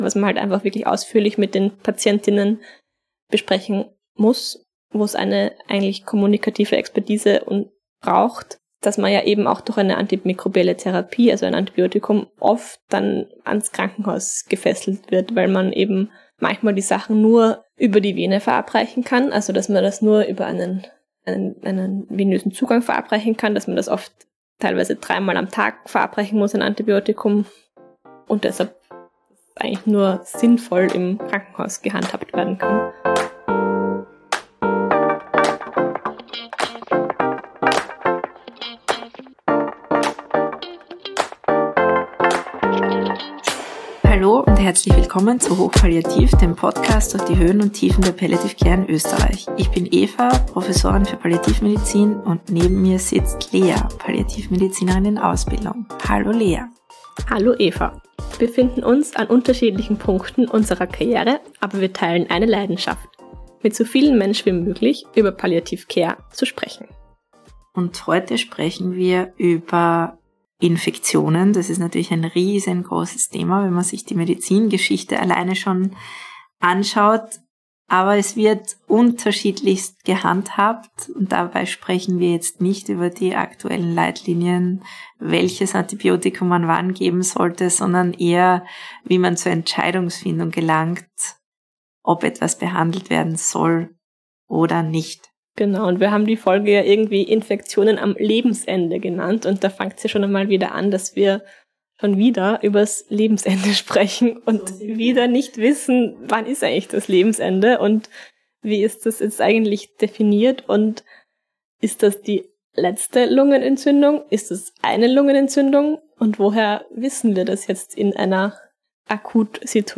Einfühlsame Kommunikation trägt dazu bei, eine informierte Entscheidung zusammen mit den Patient:innen zu treffen. Anm.: am Ende der Folge ist es leider zu technischen Problemen gekommen. Das Wichtigste könnt ihr trotzdem gut hören.